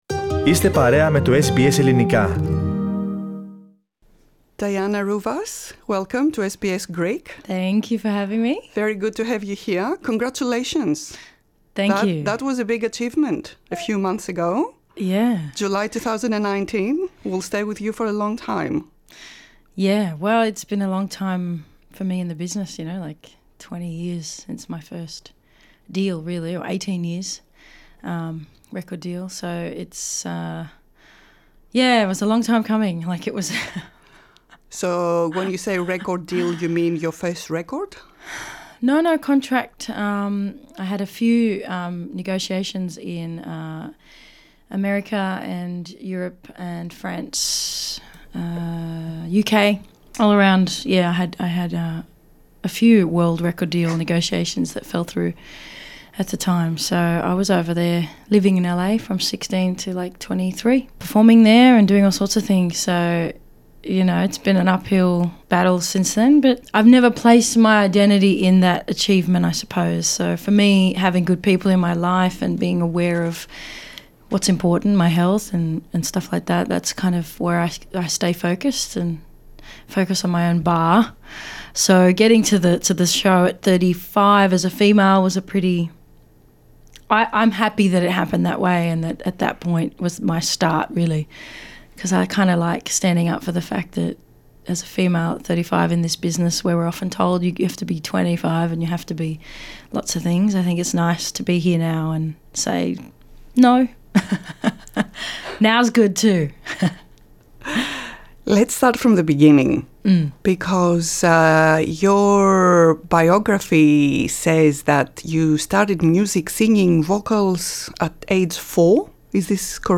Η συνέντευξη είναι στην Αγγλική.